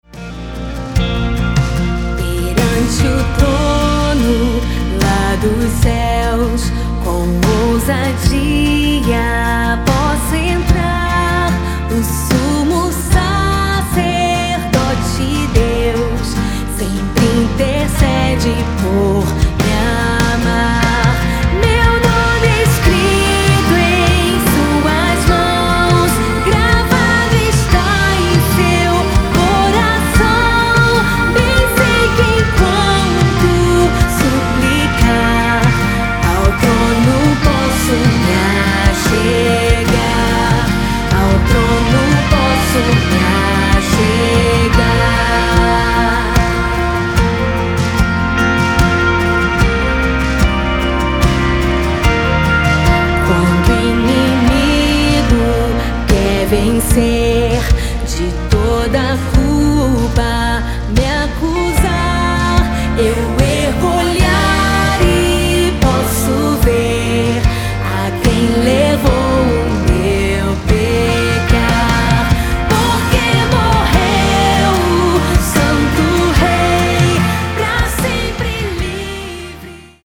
CIFRAS - TOM NO ÁLBUM (B♭)CIFRAS (C)